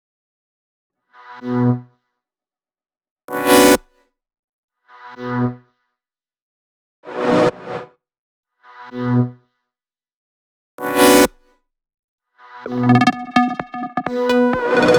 Index of /VEE/VEE2 Melody Kits 128BPM